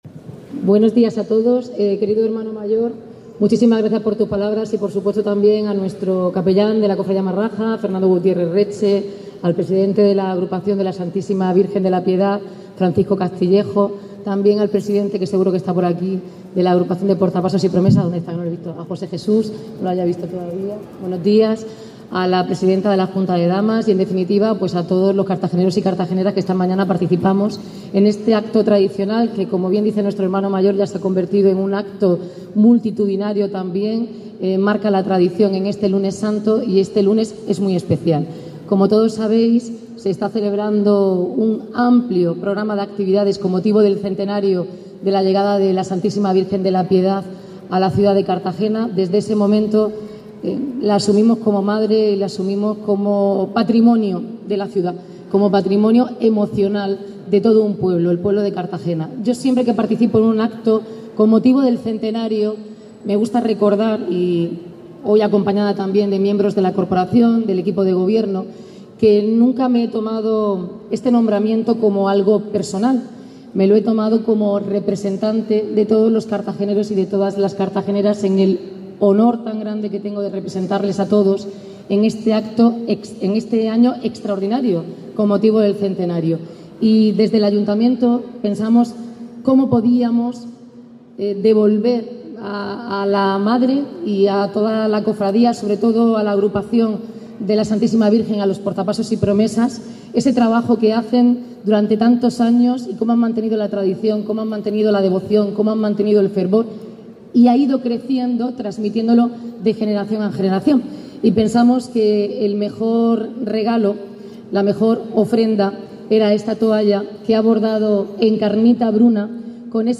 en la entrega de toalla conmemorativa a la Piedad
Declaraciones